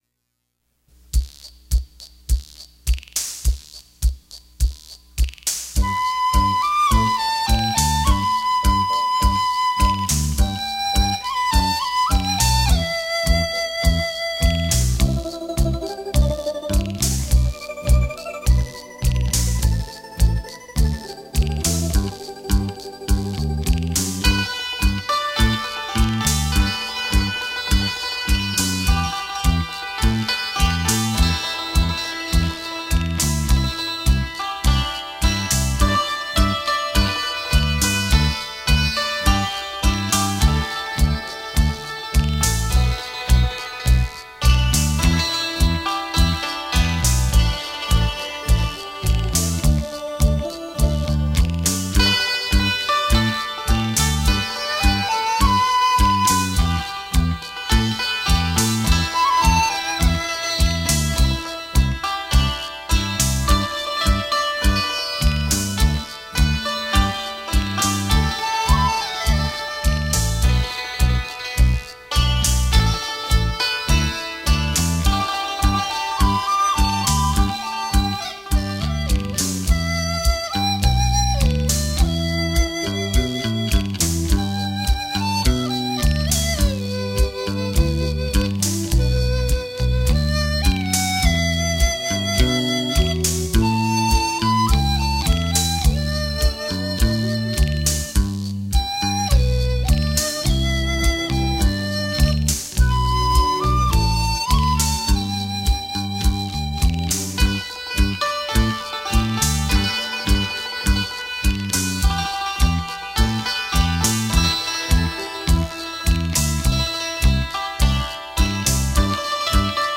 国语扬琴演奏专辑
扬琴是完全的中国情怀
幽雅飘扬的中国诗意
清清扬扬的扬琴